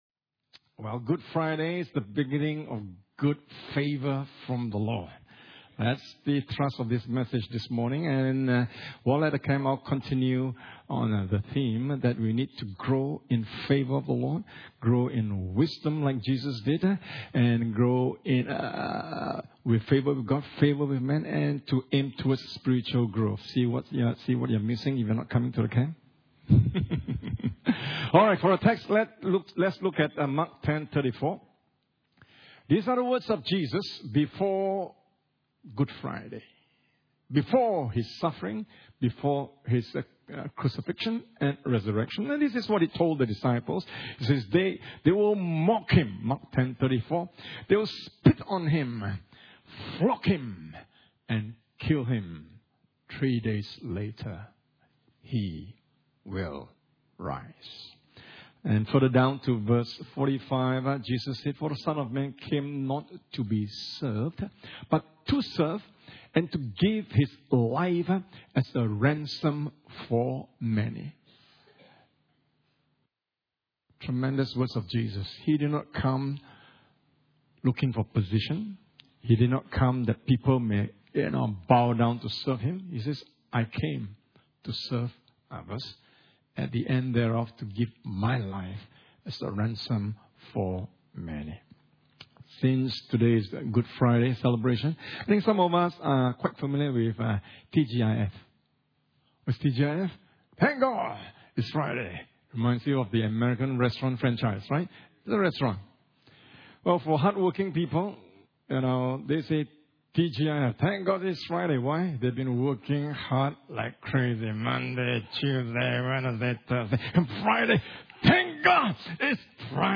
Series: Guest Speaker Service Type: Sunday Morning « Revival P3- Redigging the local well of revival What do you see in the Ressurection of Jesus?